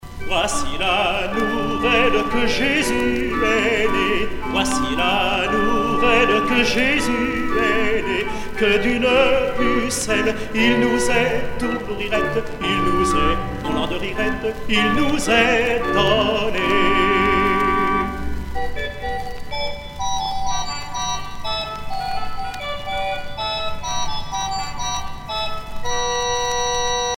Noël : Voici la nouvelle